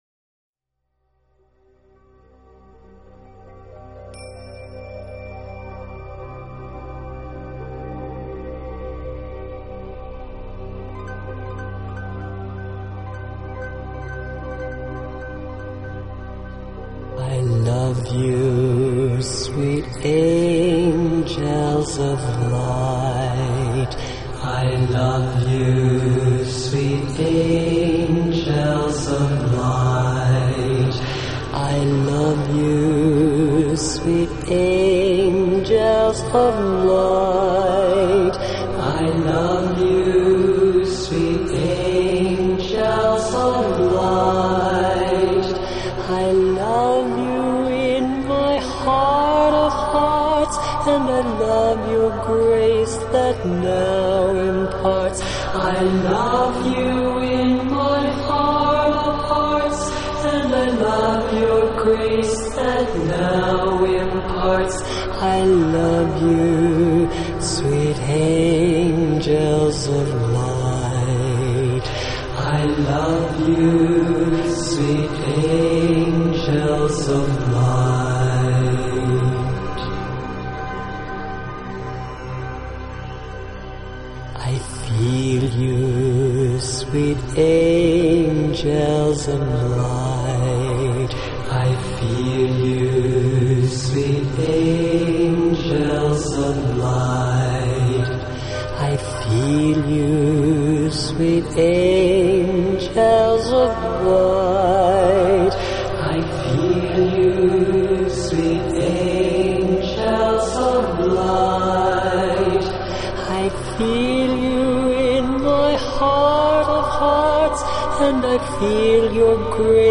Genre: New Age
Styles: New Age/Meditative